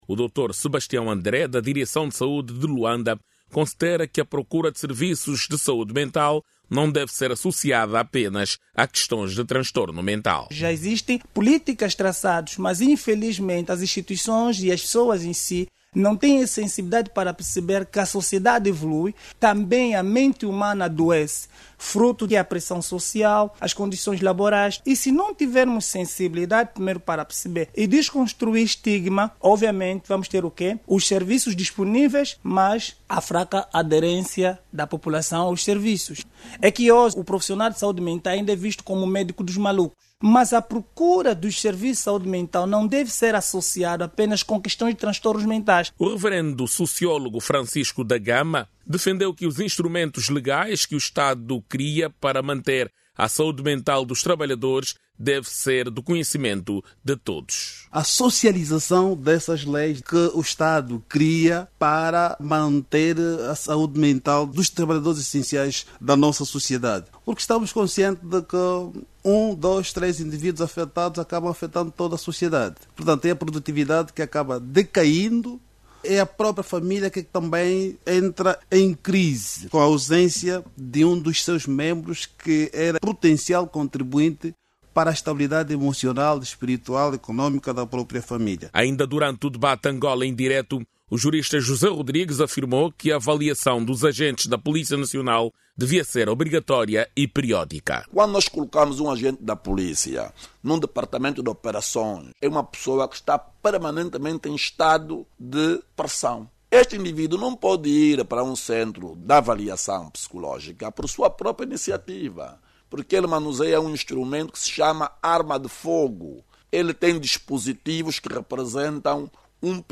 Transtornos mentais ligados estresse, ansiedade e depressão são as patologias mais comuns no país, relacionadas às doenças do fórum mental. Luanda é a província com mais casos de profissionais essenciais com problemas da saúde mental, com mais de 26 mil registos só no primeiro semestre deste ano. Ouça no áudio abaixo toda informação com a reportagem